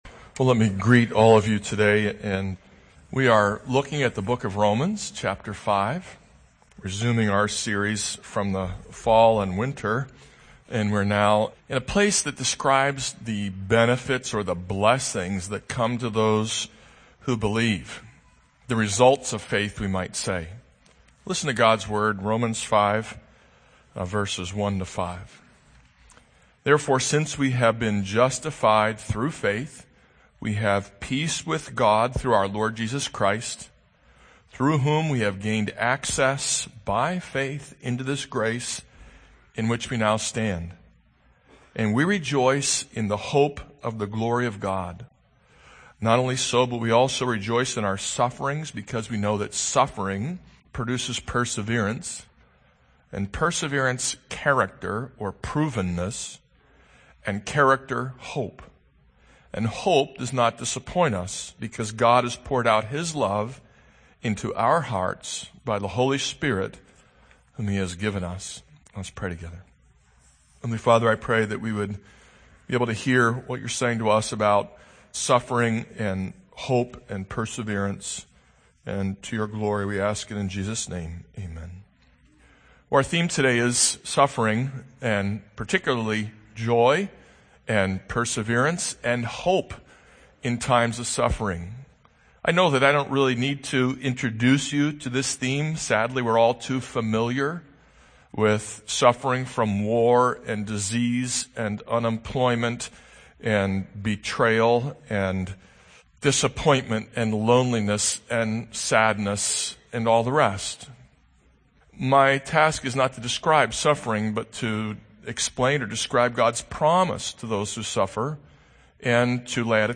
This is a sermon on Romans 5:1-5.